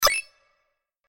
دانلود آهنگ کلیک 16 از افکت صوتی اشیاء
دانلود صدای کلیک 16 از ساعد نیوز با لینک مستقیم و کیفیت بالا
جلوه های صوتی